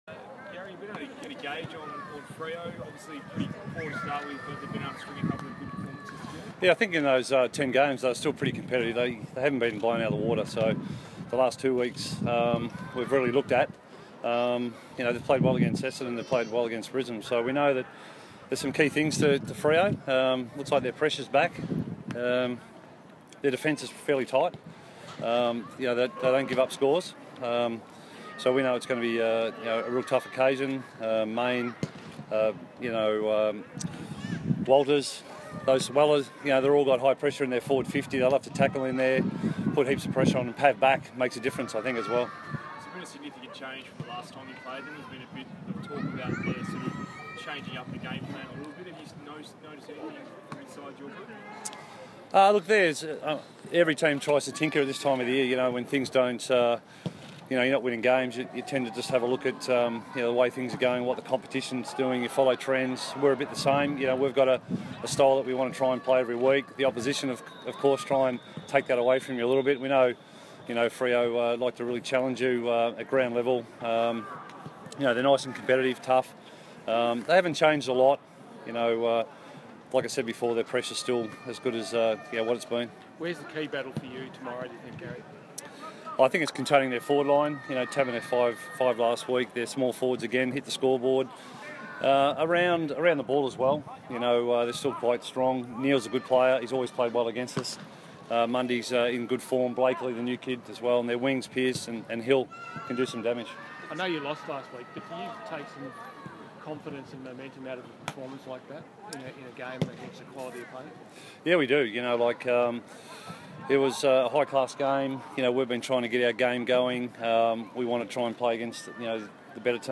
Garry Hocking Press Conference - Friday, 17 June, 20l6
Assistant Coach Garry Hocking talks to media at Domain Stadium after today's Captain's Run.